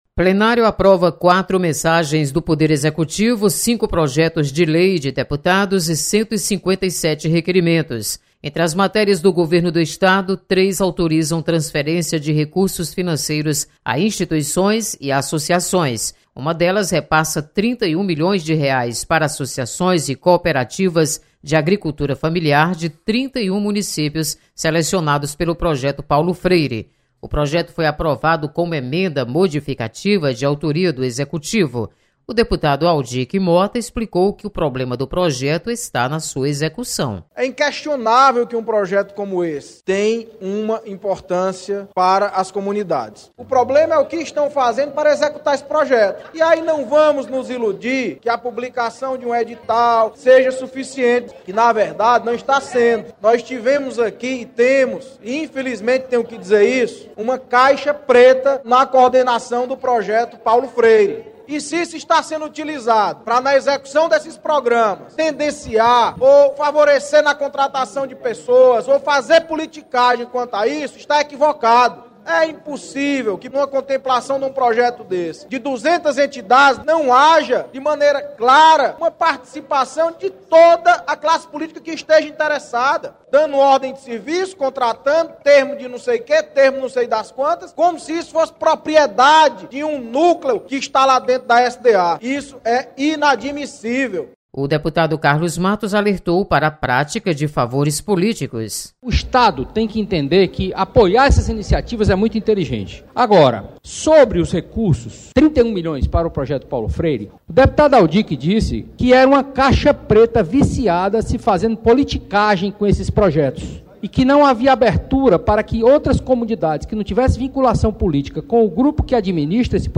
Aprovados recursos para agricultura familiar. Repórter